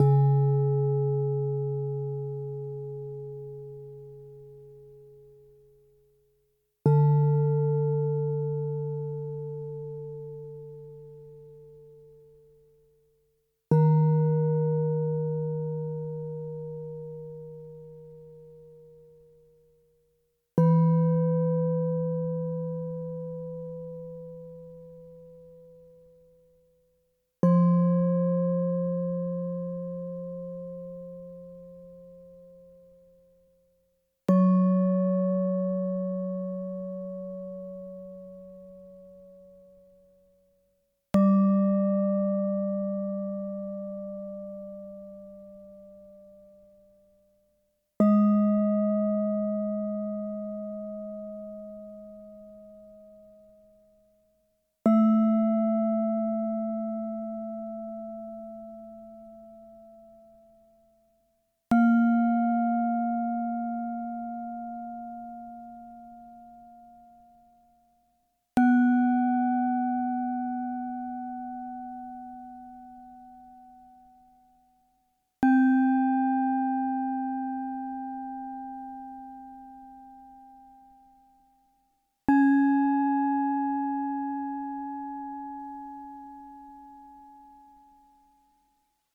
Zen Gong (Scale C#)
ambient bell bong bowl C chilled ding dong sound effect free sound royalty free Nature